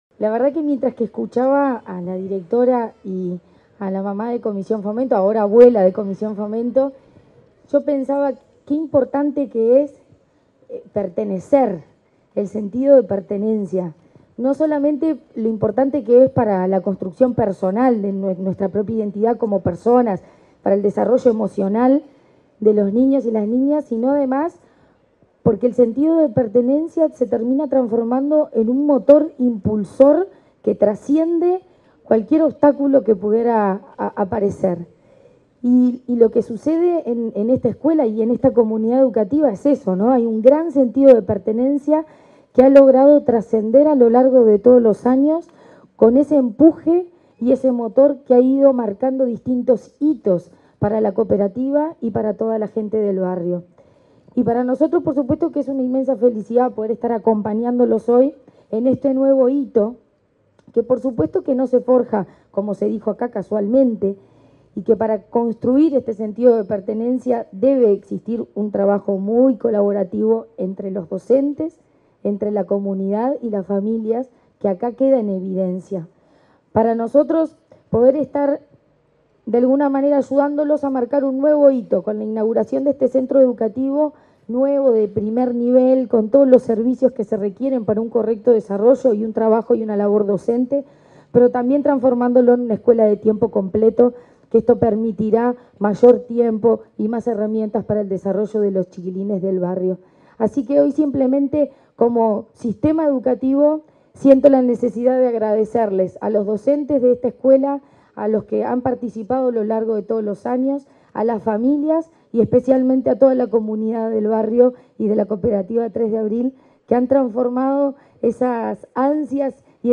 Palabras de la presidente de ANEP, Virginia Cáceres
En el marco de la inauguración del edificio de la escuela n.° 264 en el barrio 3 de Abril, Montevideo, se expresó la presidenta del Consejo Directivo